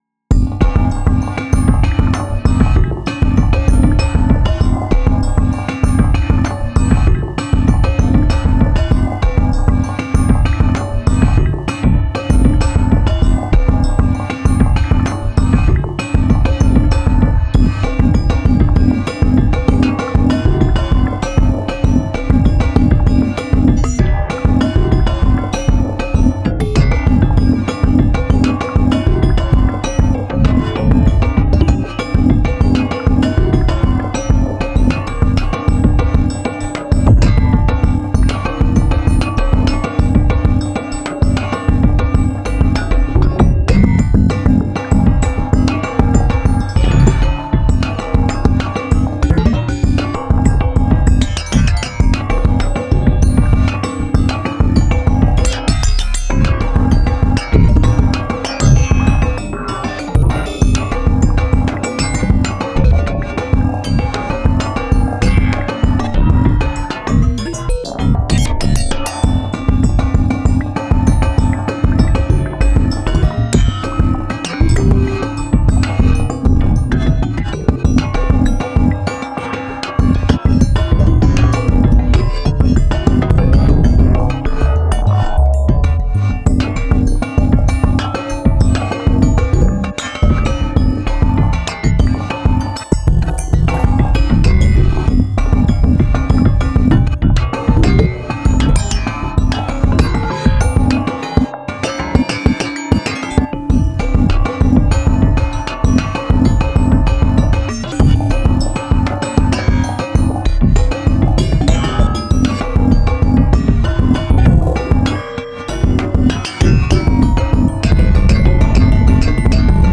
quick gong jam